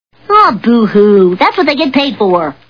The Simpsons [Bart] Cartoon TV Show Sound Bites